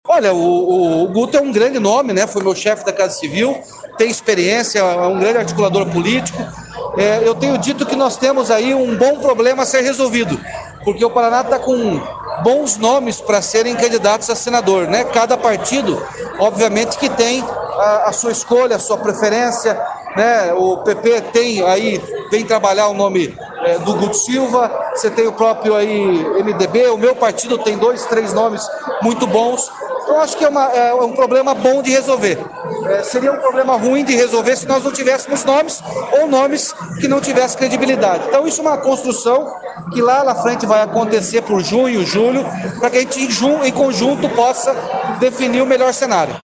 Evento contou com presenças de lideranças nacionais do partido e do governador Ratinho Junior.
Player Ouça Guto Silva, pré-candidato ao Senado